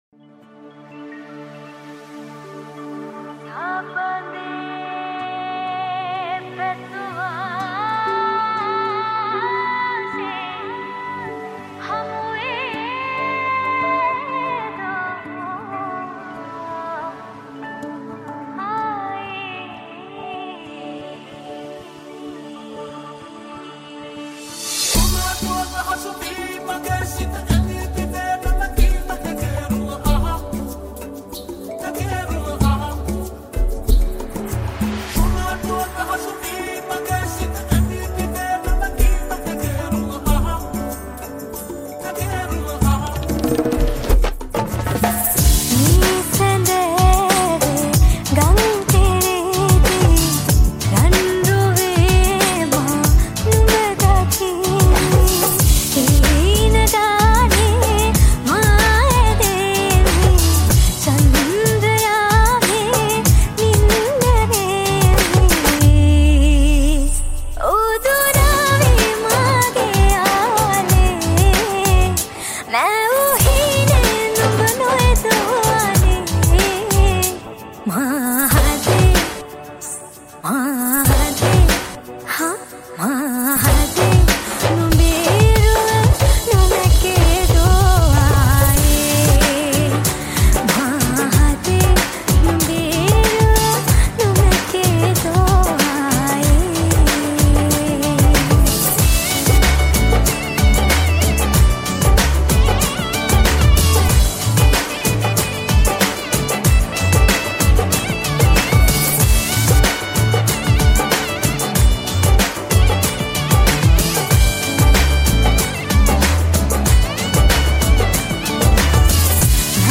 High quality Sri Lankan remix MP3 (3.4).